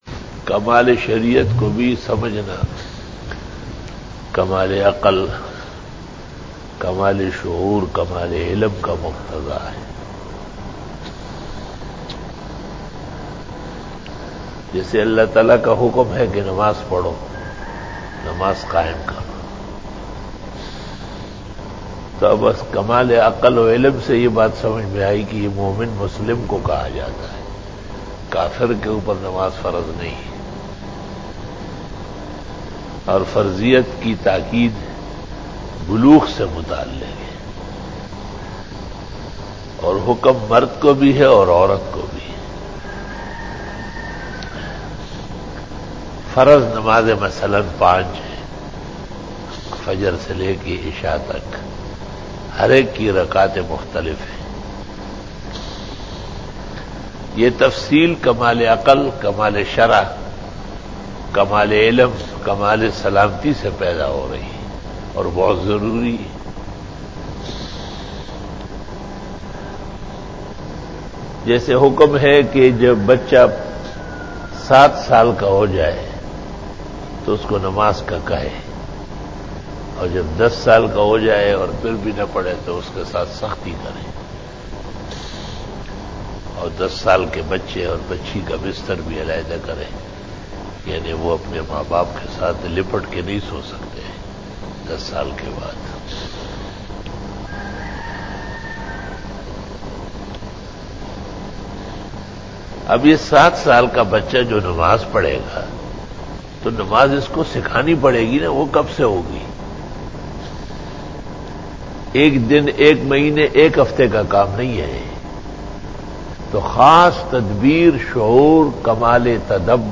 After Namaz Bayan
After Fajar Byan